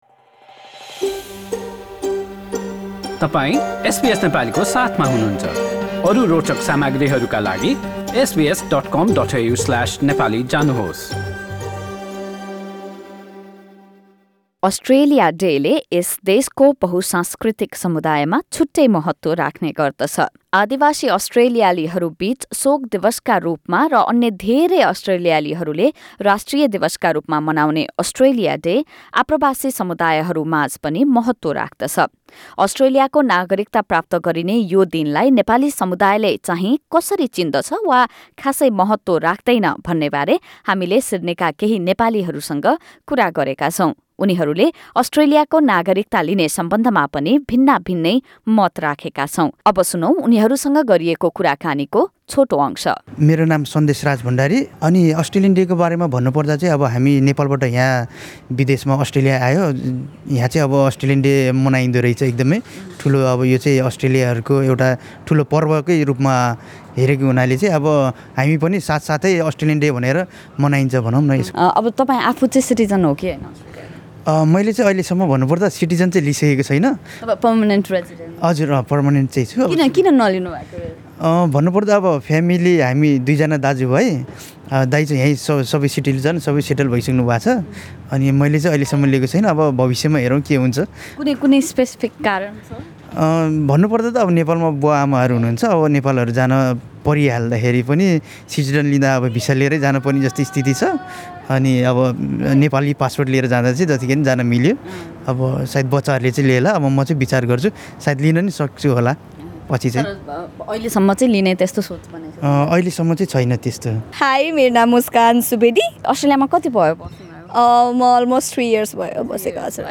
अस्ट्रेलियाको नागरिकता प्राप्त गरिने यो दिनलाई नेपाली समुदायले चाहिँ कसरी लिन्छ त? यो दिनले केही महत्त्व राख्छ वा राख्दैन भन्नेबारे हामीले केही नेपालीहरूसँग कुराकानी गरेका छौँ।